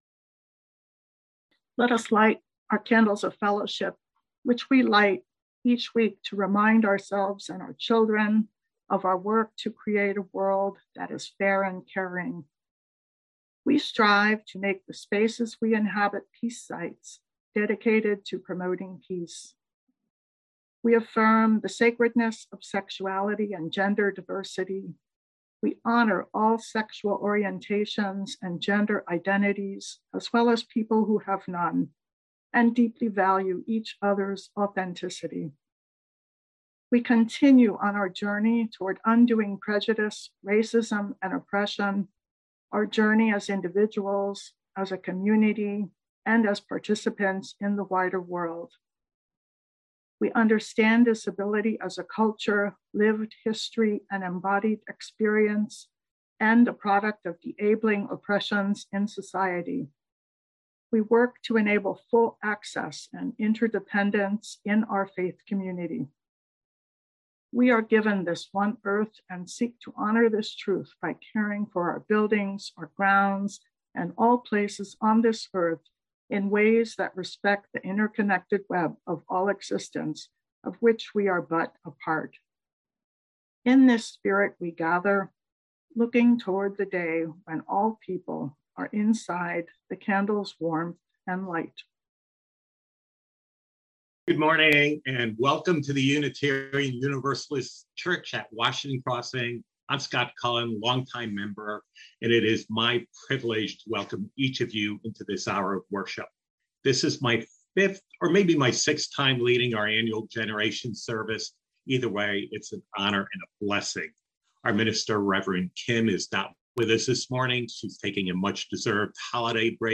Sunday-Service-January-2-2022-Generations-Service